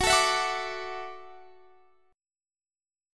Listen to Gbm7#5 strummed